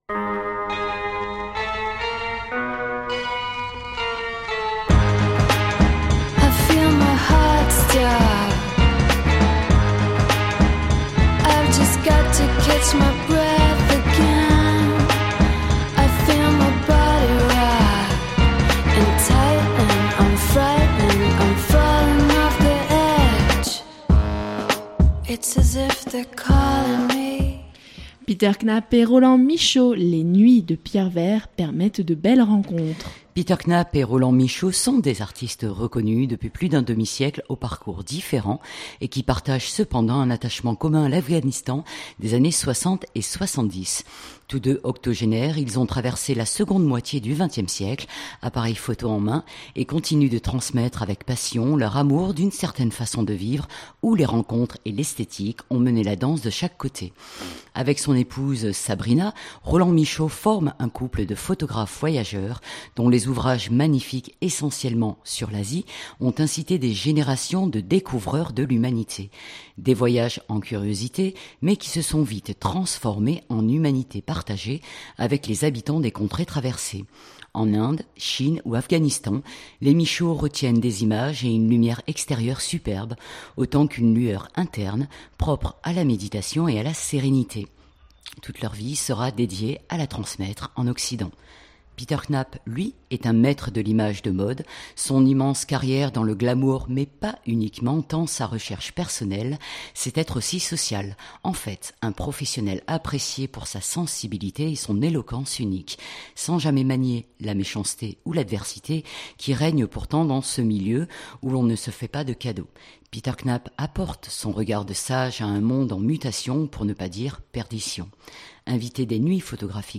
Invité des Nuits Photographiques de Pierrevert, Roland Michaud a visité l’exposition de Peter Knapp à la Fondation Carzou de Manosque et ce fut l’occasion d’une rencontre éblouissante de chaleur et de générosité.
C’est à une grande réflexion sur le temps et l’art de l’image avec deux photographes de légende que nous vous convions… écouter ou télécharger Durée : 28'08" Knapp & Michaud.mp3 (21.09 Mo)